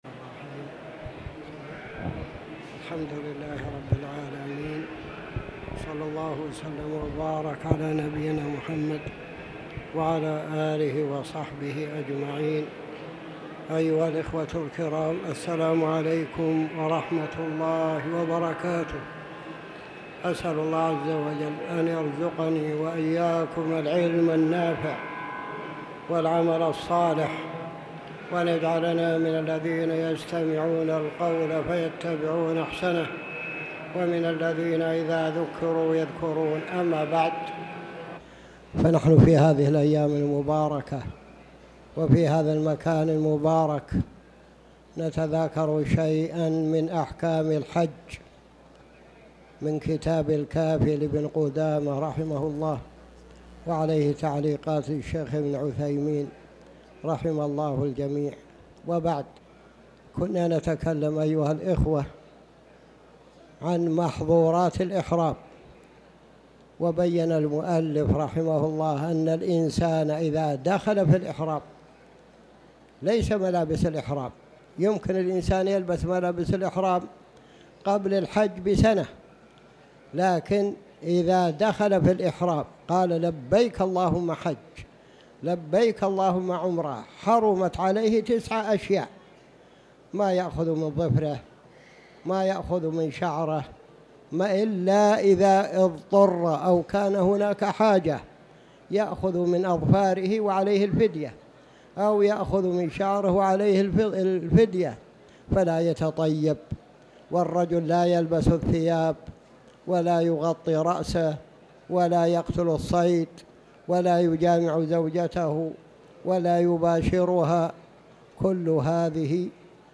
تاريخ النشر ٢ ذو الحجة ١٤٤٠ هـ المكان: المسجد الحرام الشيخ